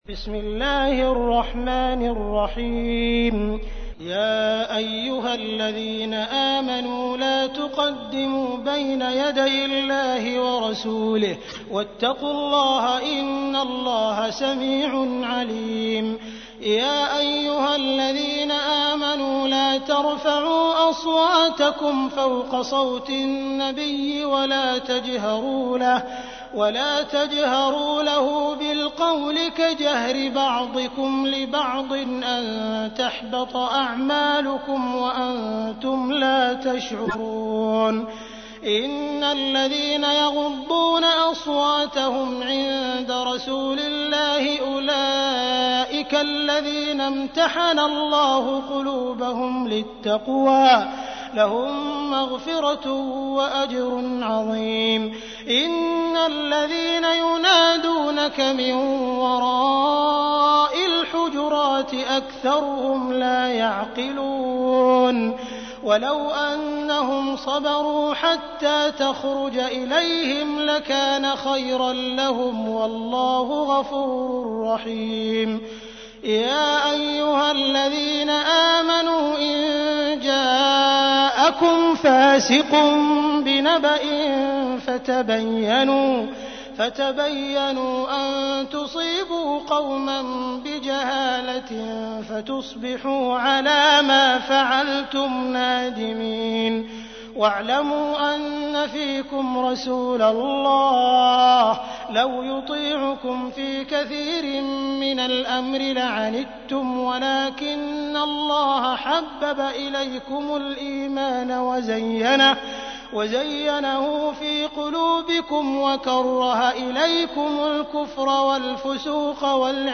تحميل : 49. سورة الحجرات / القارئ عبد الرحمن السديس / القرآن الكريم / موقع يا حسين